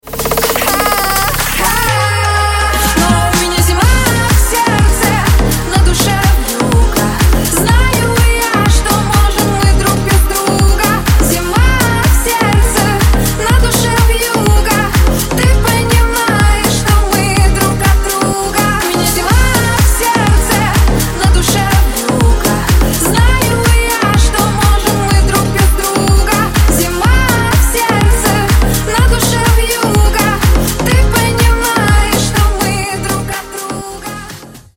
Клубные Рингтоны
Рингтоны Ремиксы » # Танцевальные Рингтоны